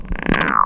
rocket_idle_chirp5.wav